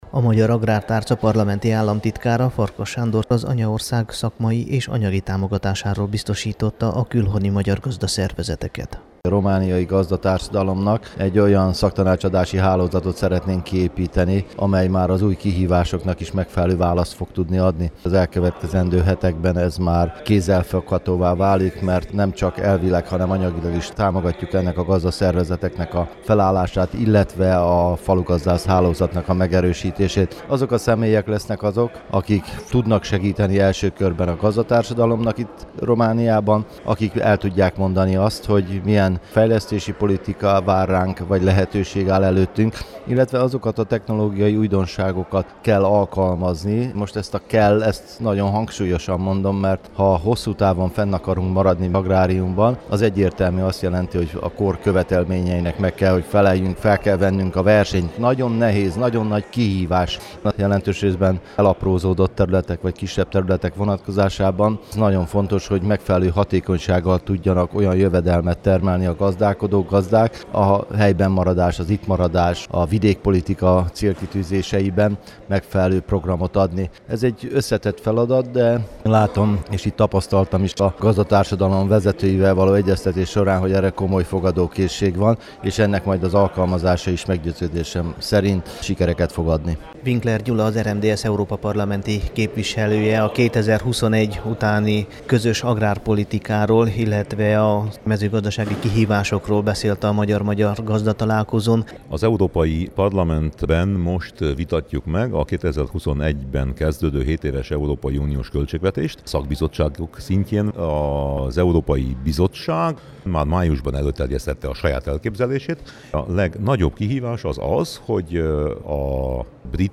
Az Agromalim nemzetközi mezőgazdasági vásár keretében tartották meg szombaton Aradon a 19. Magyar–magyar gazdatalálkozót az Arad Megyei Magyar Gazdák Egyesületének szervezésében. Az erdélyi magyar gazdatársadalom, a Kárpát-medencei gazdaszervezetek képviselői, a magyar szaktárca vezetői és a szakpolitikusok részvételével tartott fórumon a 2021 után közös európai agrárpolitika, a mezőgazdasági és vidékfejlesztési támogatások várható alakulása és az érdekképviselet megerősítésének fontossága szerepelt napirenden.
xix-ik_magyar-magyar_gazdatalalkozo.mp3